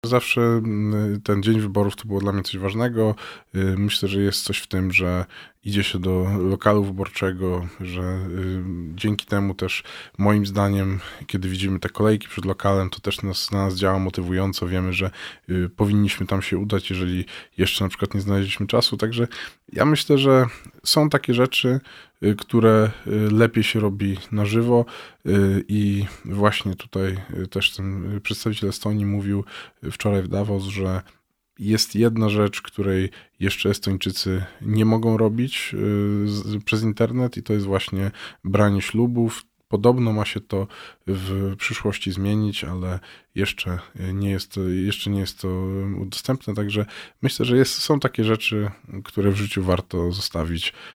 Odpowiada Janusz Cieszyński – sekretarz stanu w KPRM, pełnomocnik rządu do spraw cyberbezpieczeństwa.